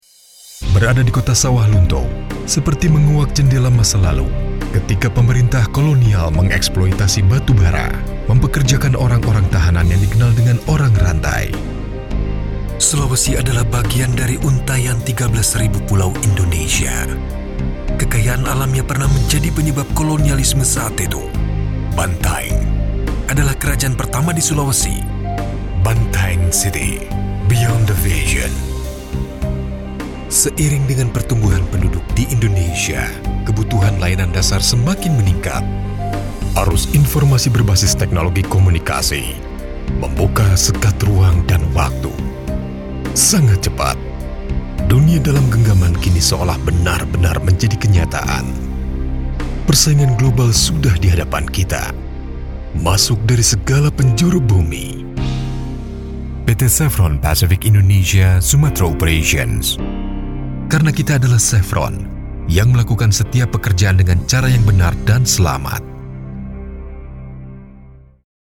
WARM VOICE & DRAMATIC
Sprechprobe: Industrie (Muttersprache):